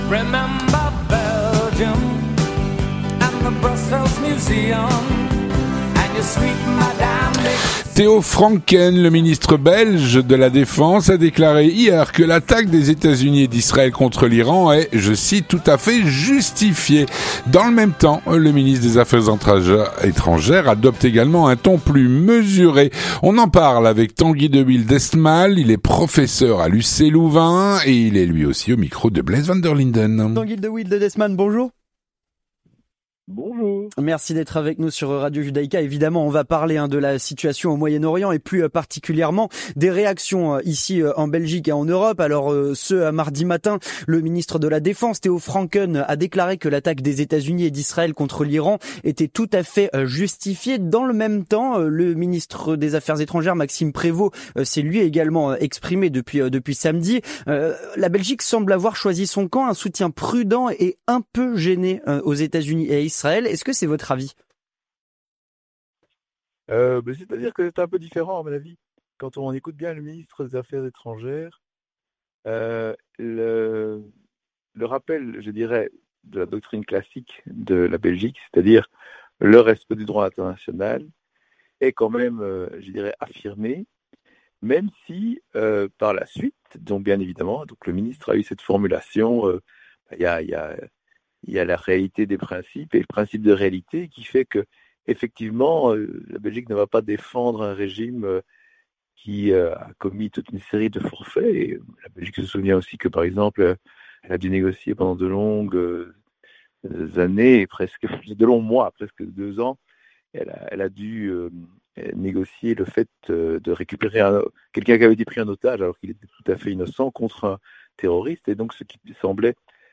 Il en parle au micro